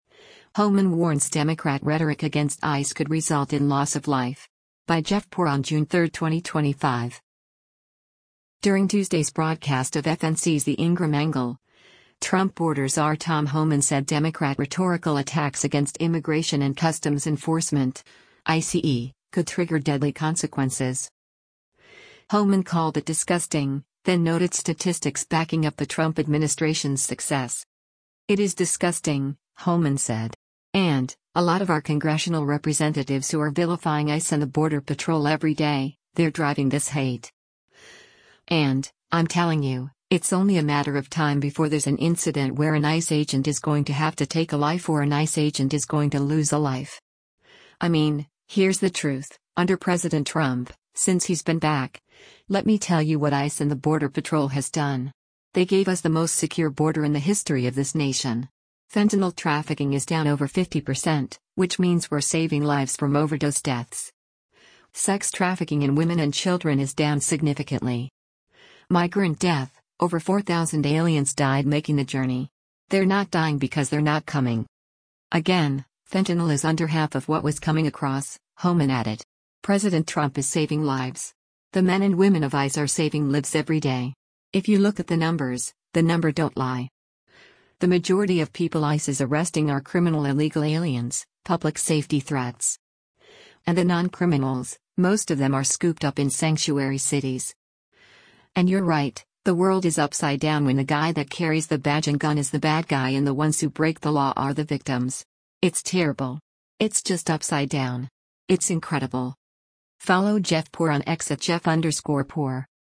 During Tuesday’s broadcast of FNC’s “The Ingraham Angle,” Trump border czar Tom Homan said Democrat rhetorical attacks against Immigration and Customs Enforcement (ICE) could trigger deadly consequences.